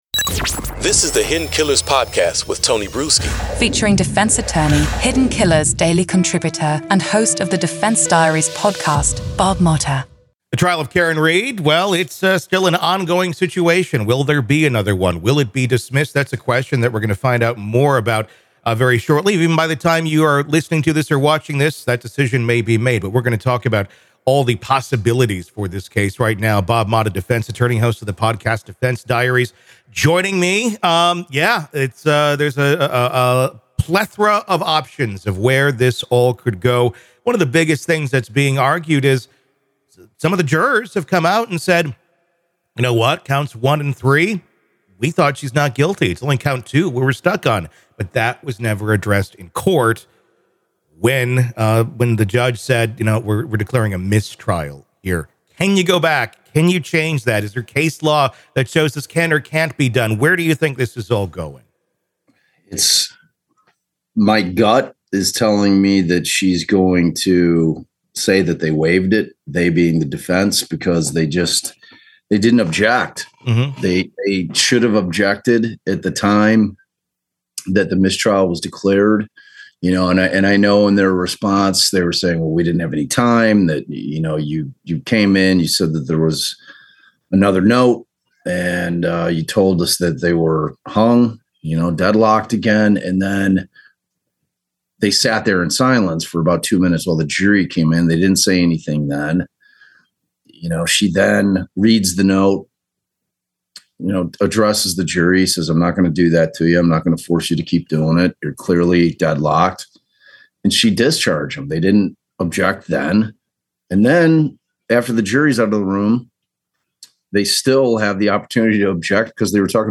True Crime News & Commentary / What Is Karen Read's Future After A Hung Jury?